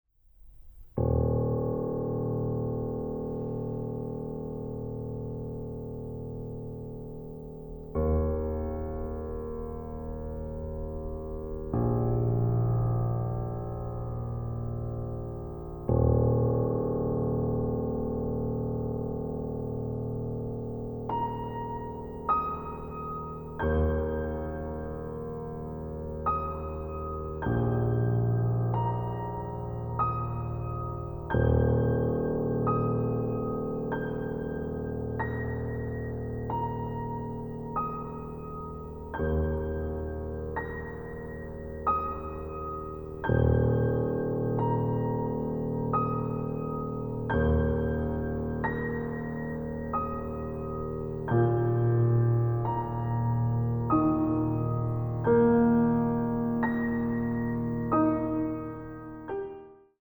Genre: Choral music
Instrumentation: mixed choir